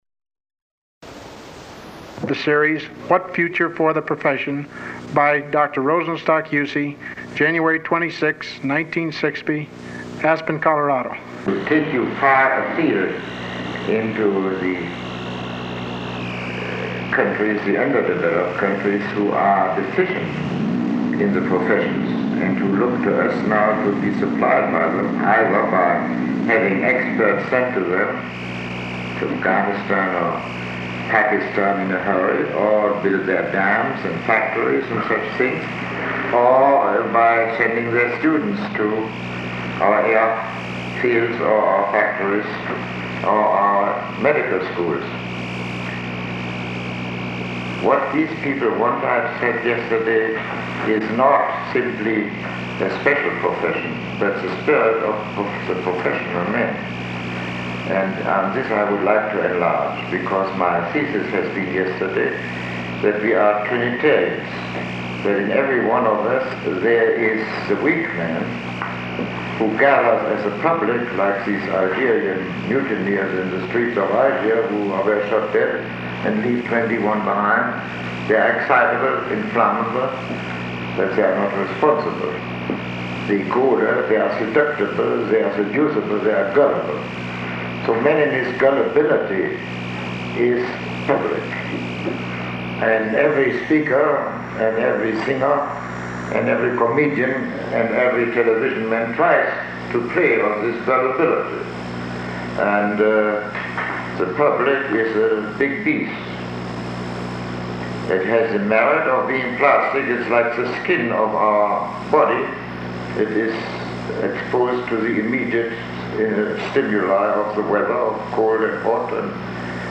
Lecture 02